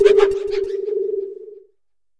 Cri de Bérasca dans Pokémon HOME.